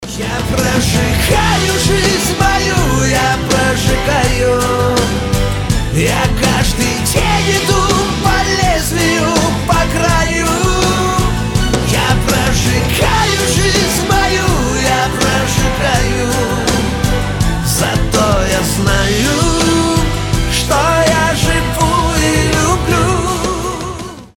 • Качество: 320, Stereo
мужской вокал
громкие
русский шансон
русская эстрада